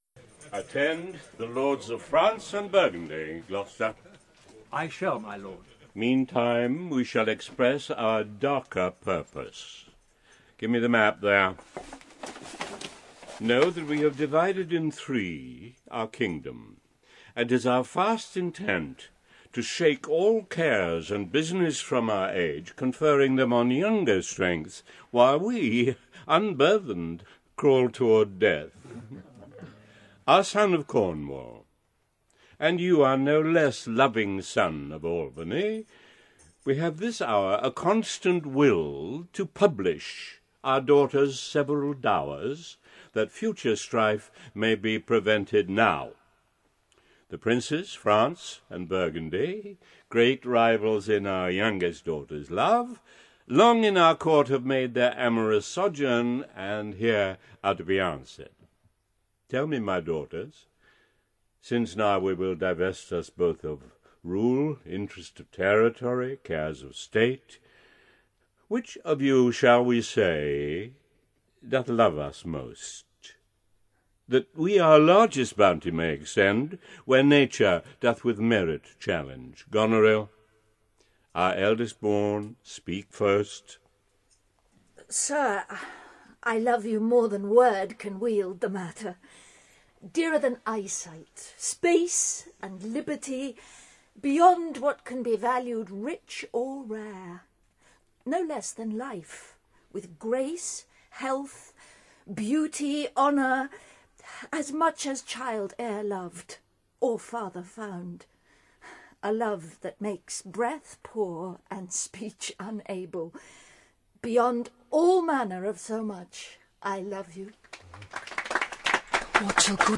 Ukázka z knihy
king-lear-en-audiokniha